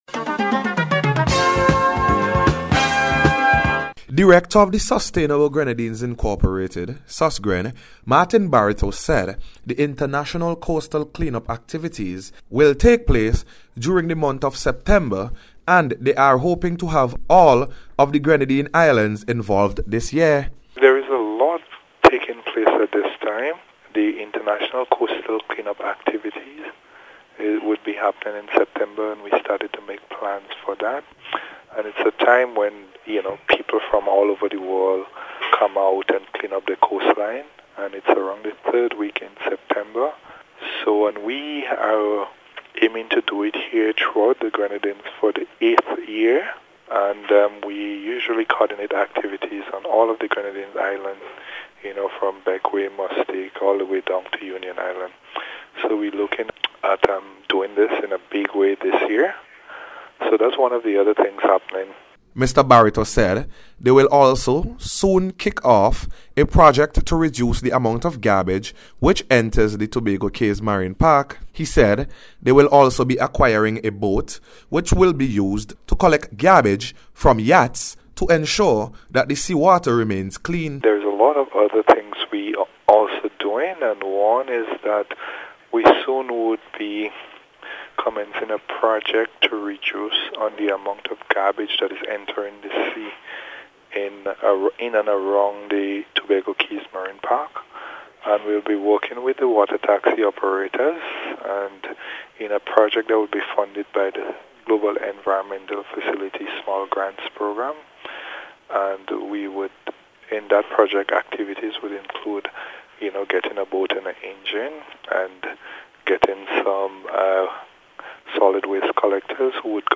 SUSGREN-AND-CLEAN-UP-DAY-REPORT.mp3